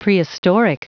Prononciation du mot prehistoric en anglais (fichier audio)
Prononciation du mot : prehistoric